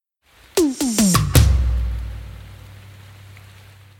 پاساژ ۶/۸ بستکی مارکتی
در پکیج پاساژ ۶/۸ بستکی مارکتی با استفاده از وان شات های مختلف اقدام به ساخت پاساژها مارکتی کرده ایم که میتوانید به راحتی در پروژه خودتان از آنها استفاده کنید
سرعت تمام سمپل های پکیج پاساژ ۶/۸ بستکی مارکتی روی ۱۰۵bpm و در استایل ۶/۸ بستکی هستند. تنظیم کوانتایز در ۴/۴ تریپلت بوده است.